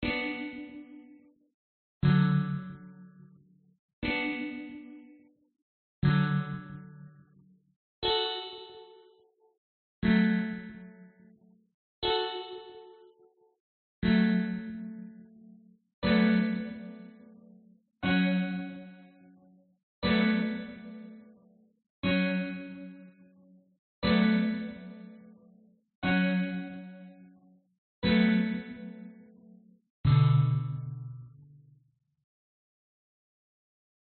描述：在 "Rapture "软合成器（"Mu3eum "复古合成器扩展）上演奏的和弦。
Tag: Korg Polysix B_minor 和弦 Rapture Mu3eum Soft_synth 复古_synth